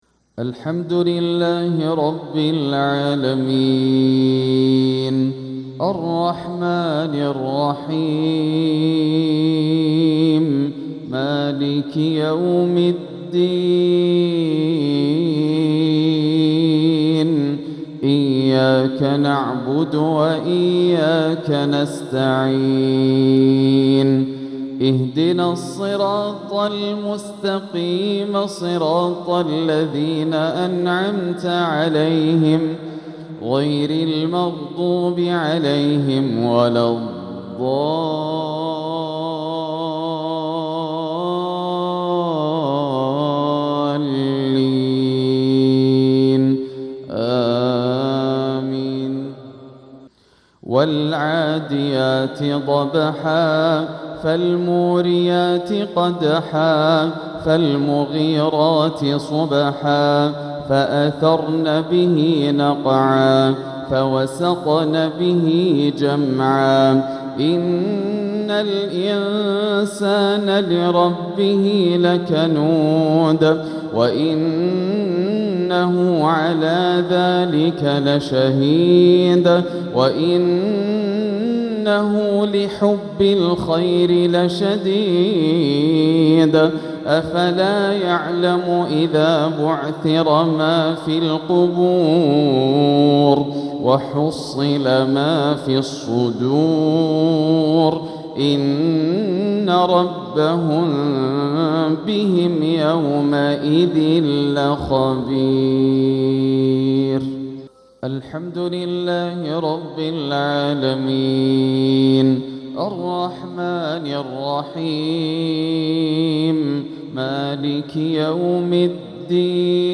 سورتي العاديات وقريش | مغرب الجمعة 7 صفر 1447هـ > عام 1447 > الفروض - تلاوات ياسر الدوسري